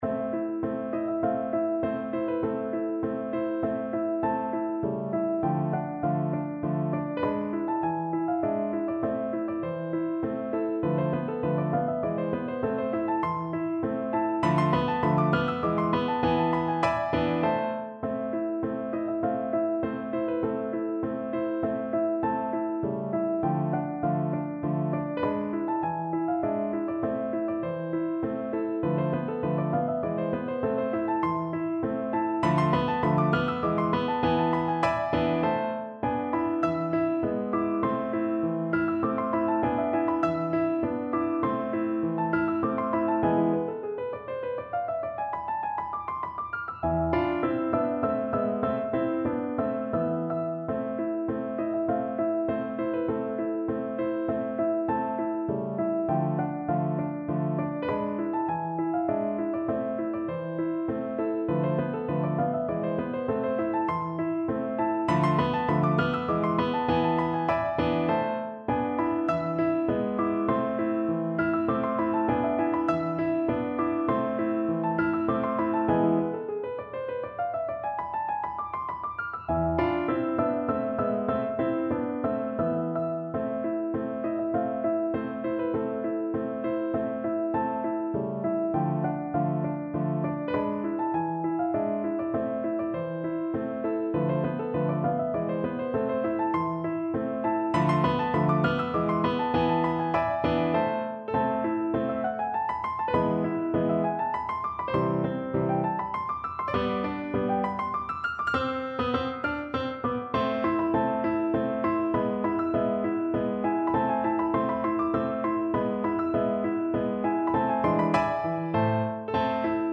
Classical Ogiński, Michał Kleofas Polonaise 'Pozegnanie Ojczyzny' Piano version
No parts available for this pieces as it is for solo piano.
A minor (Sounding Pitch) (View more A minor Music for Piano )
Moderato
3/4 (View more 3/4 Music)
Classical (View more Classical Piano Music)